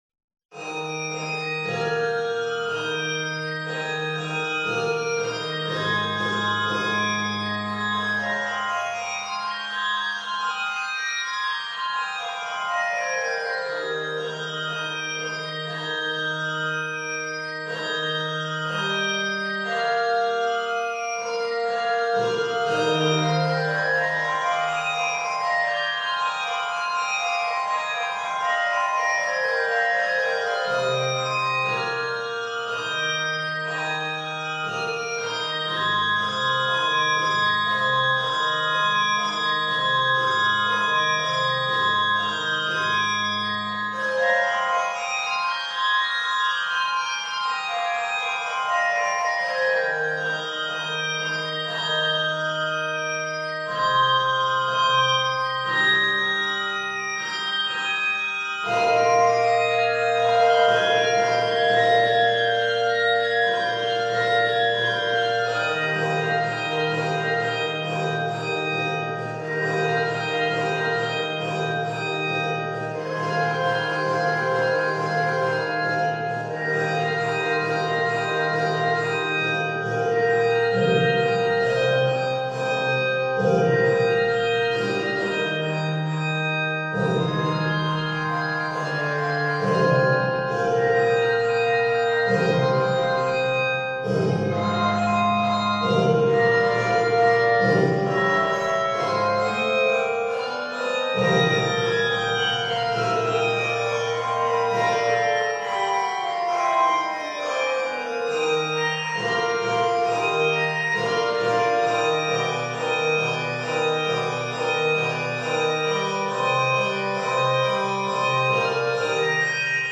orchestral chimes, bell tree
Key of Eb Major.